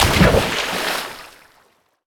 water_splash_object_body_04.wav